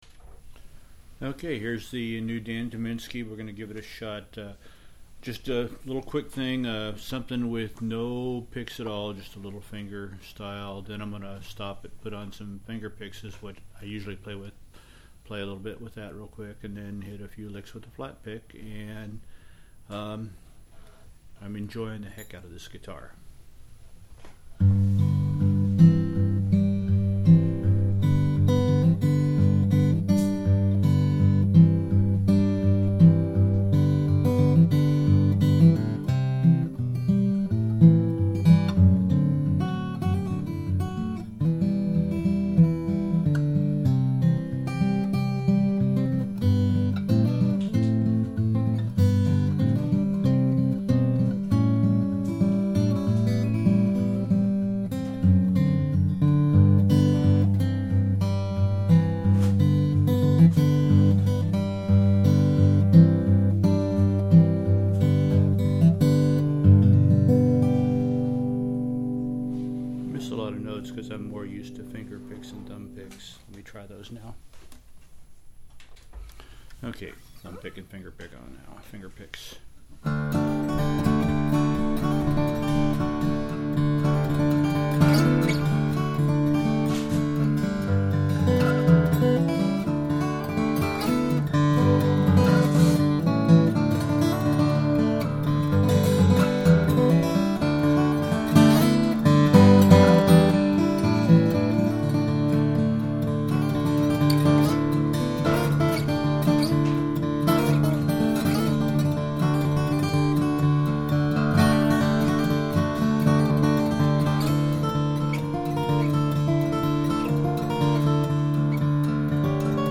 Звучит здорово на этих звуковых клипах.
Придирчивость неаккуратна, а улавливание пальцев хуже, но, по крайней мере, вы можете услышать немного о новом Tyminski.
Человек, для неаккуратной игры, эта гитара звучит очень мило. ;) Гитара с великолепным звучанием у вас там.